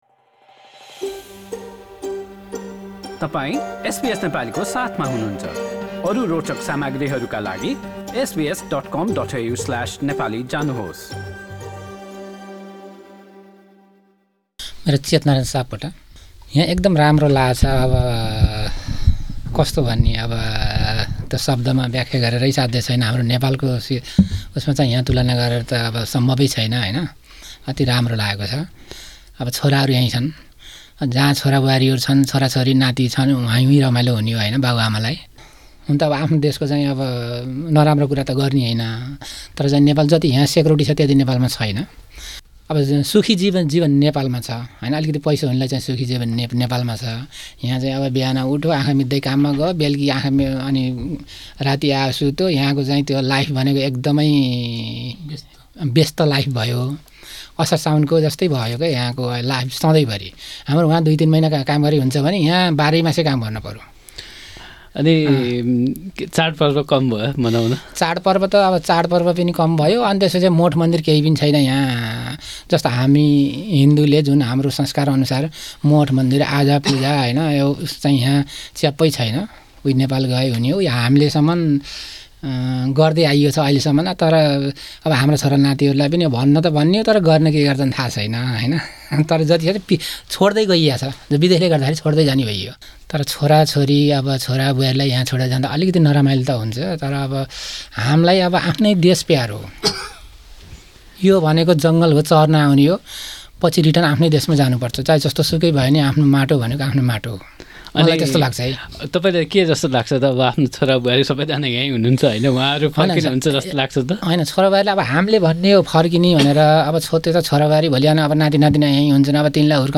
उहाँहरूसँग गरिएको कुराकानी छोटो अंश: Source